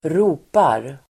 Uttal: [²r'o:par]